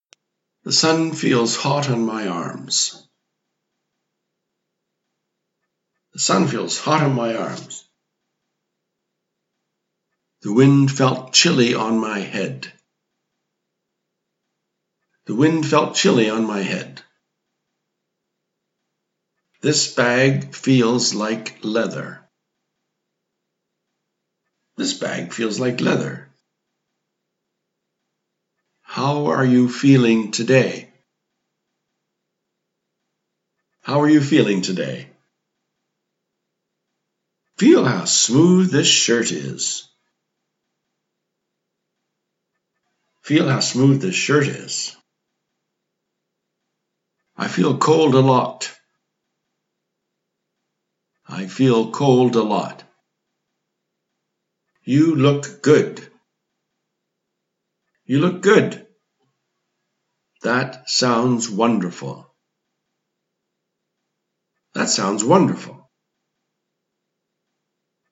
아래 연습 문장들은 원어민 녹음이 들어있으니 원하는 분들을 따라 읽어 보시길...
연습 문장